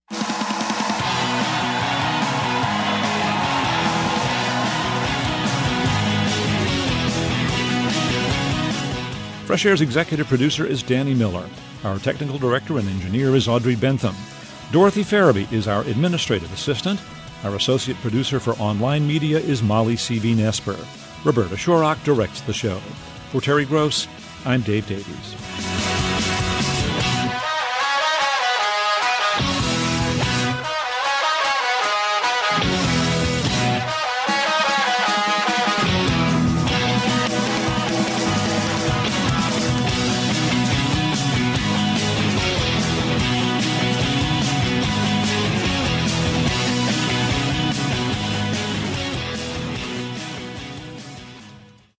a minute-long extract played over the closing credits